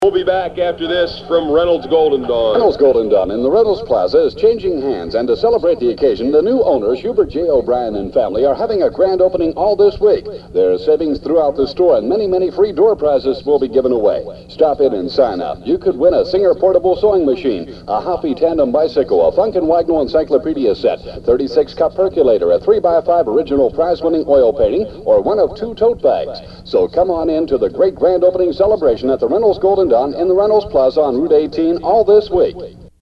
* Old Radio Commercials -